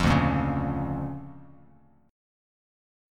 DmM7#5 chord